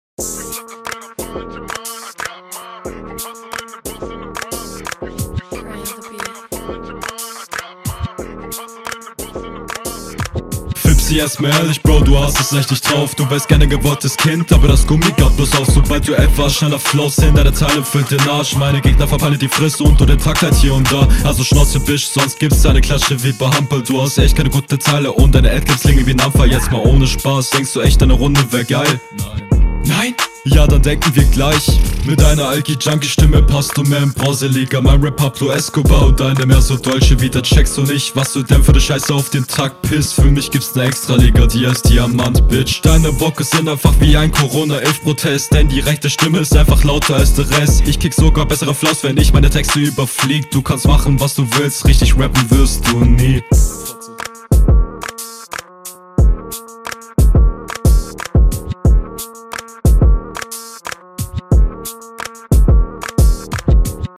Flow zwar vor allem in …